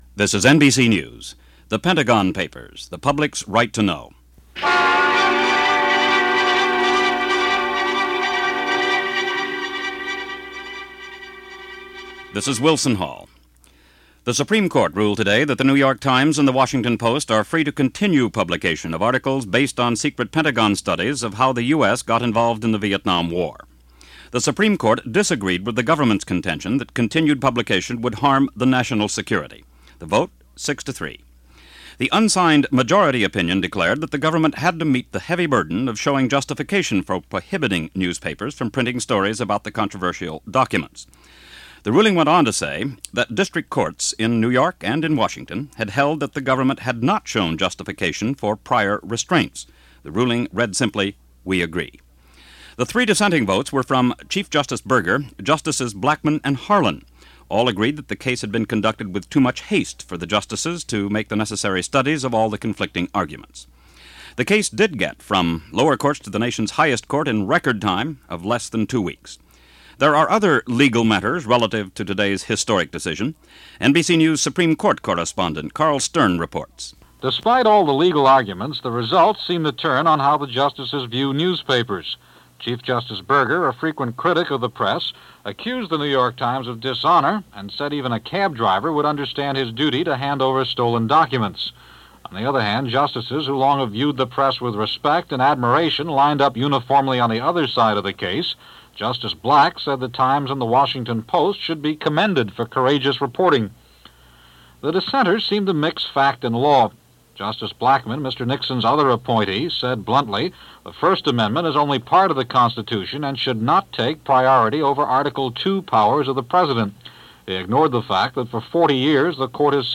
NBC Radio Special